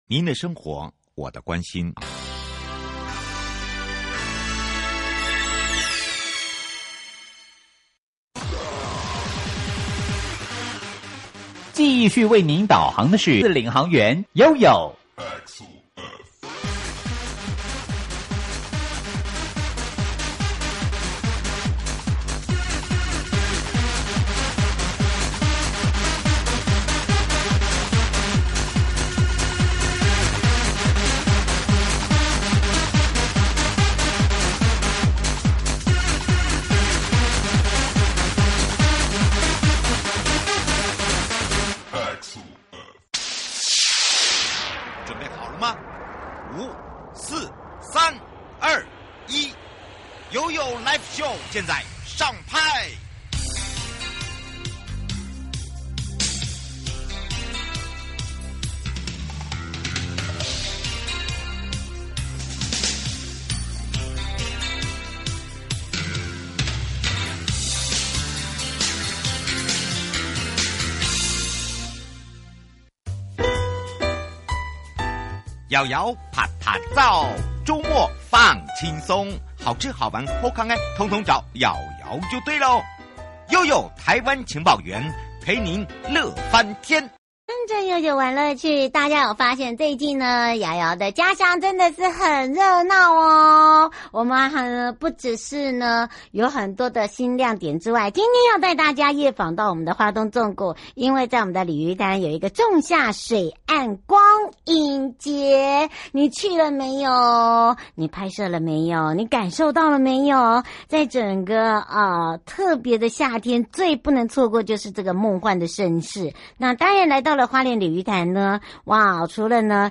受訪者： 1.花東縱谷管理處許宗民處長